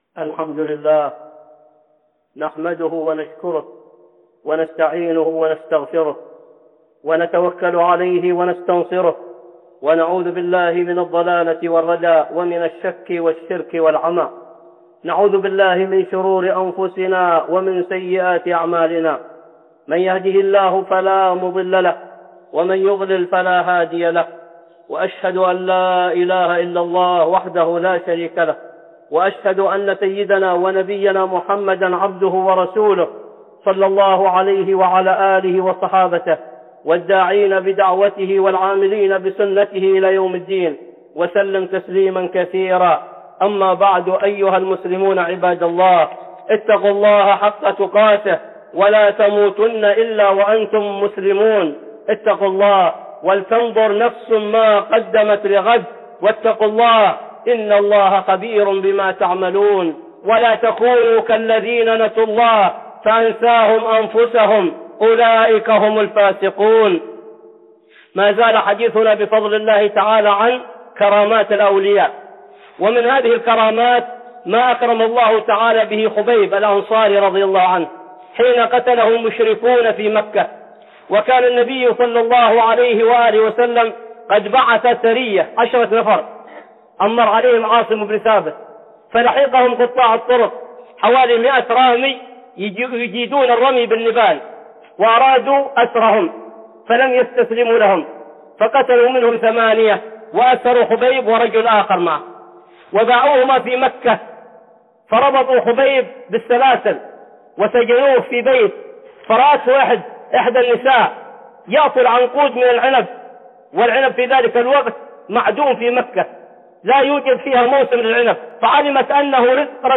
(خطبة جمعة) كرامات الأولياء 3